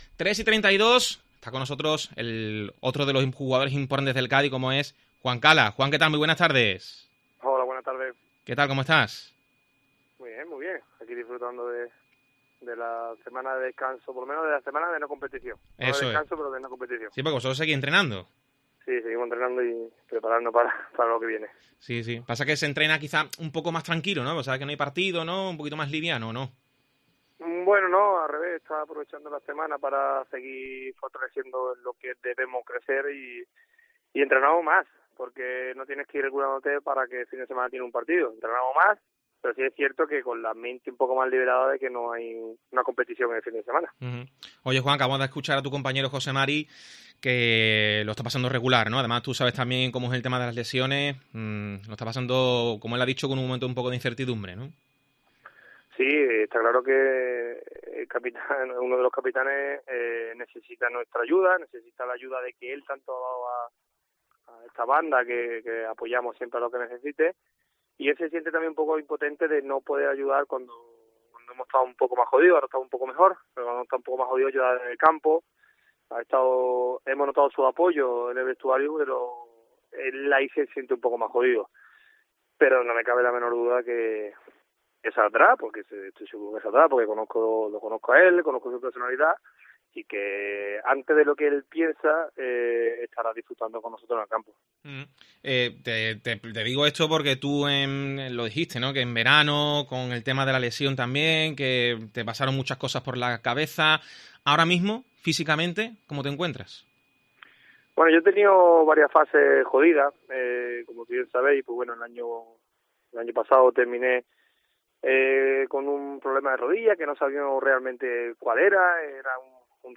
El jugador ha pasado por los micrófonos de COPE Cádiz donde ha analizado la temporada cadista, su situación individual y todo lo que rodea al conjunto gaditano.